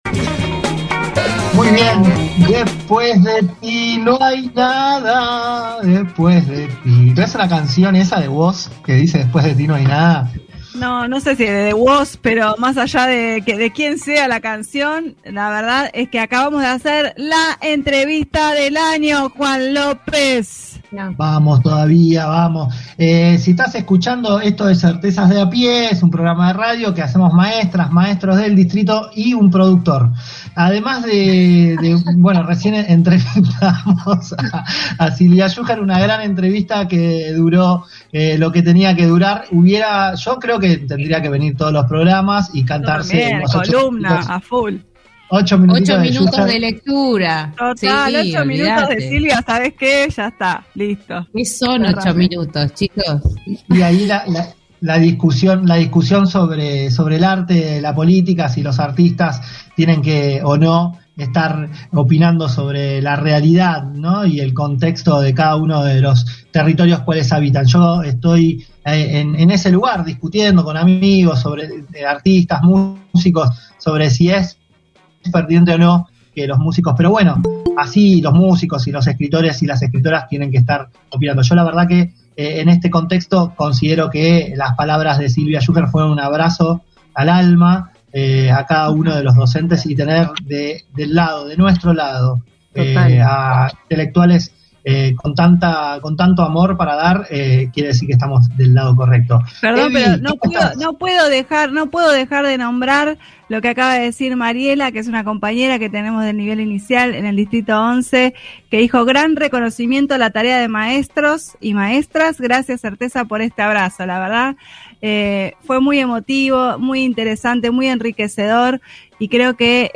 Entrevista a Paula Arraigada - 05/07/21